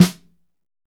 Index of /90_sSampleCDs/Northstar - Drumscapes Roland/SNR_Snares 2/SNR_P_C Snares x